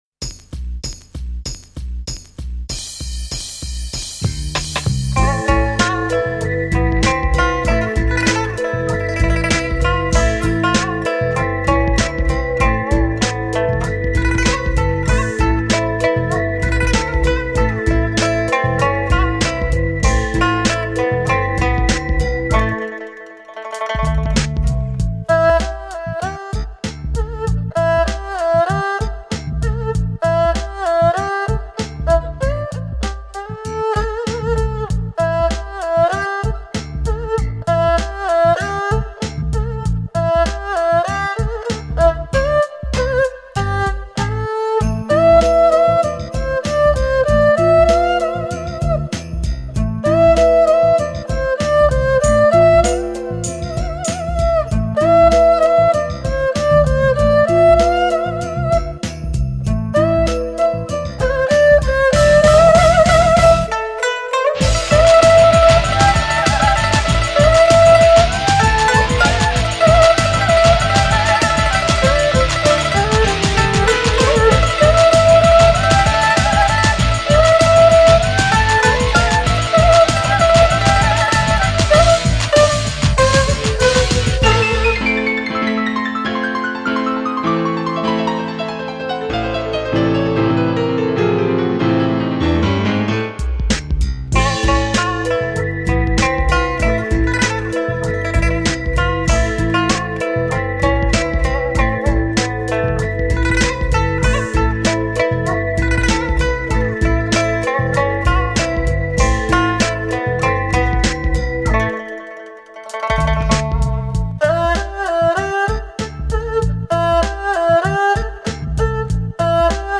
二胡
琵琶
bass
drum
keyboard
酸民乐，一种用民乐作为旋律乐器与电子舞曲、HIP-HOP相结合的音乐风格。
的风格大多是Lounge、Hip-Hop与Funk等风格结合在一起的某些句子或称“Groove”。